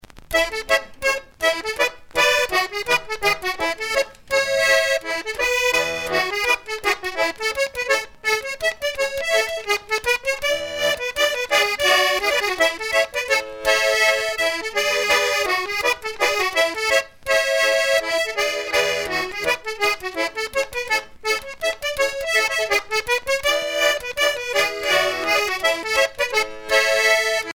Mémoires et Patrimoines vivants - RaddO est une base de données d'archives iconographiques et sonores.
Mazurka
Région ou province Morvan
danse : mazurka